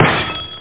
Amiga 8-bit Sampled Voice
1 channel
bottlesmash.mp3